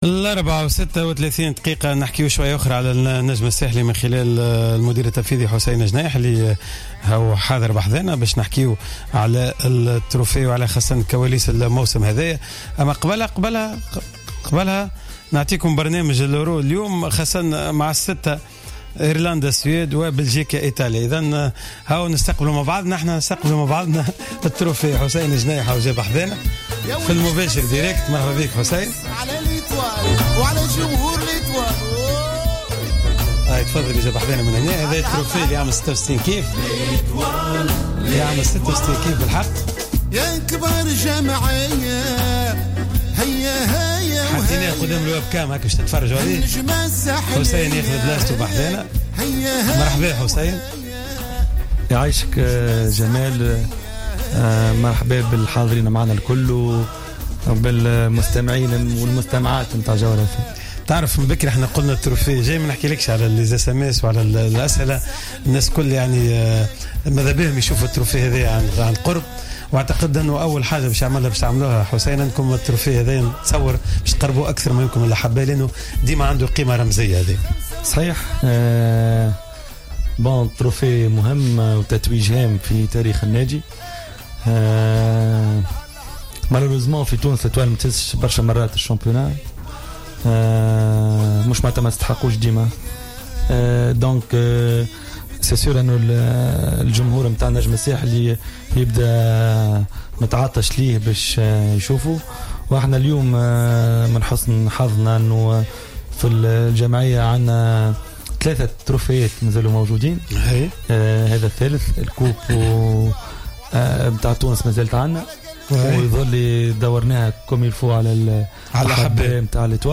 رمز البطولة في إستديوهات جوهرة أف أم
وفي ما يلي التسجيل الصوتي للحصة :